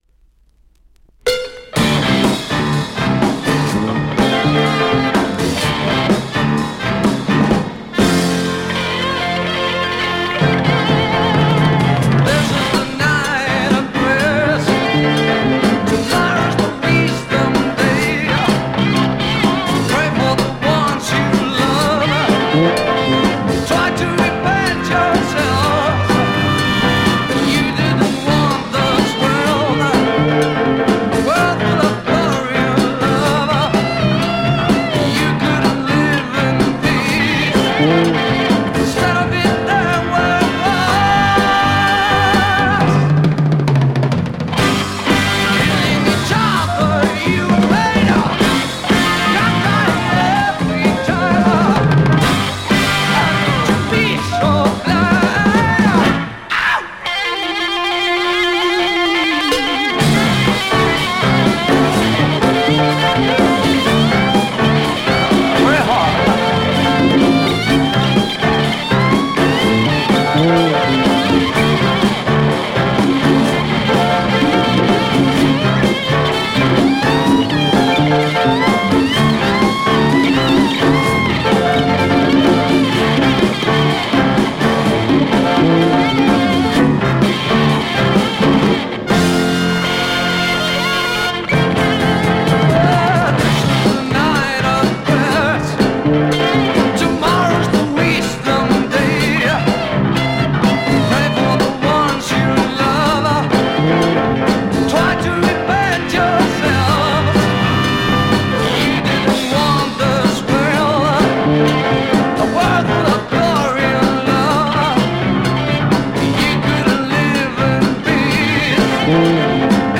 Mexican Heavy psych rock dancer
Powerful Mexican hard psych dancer !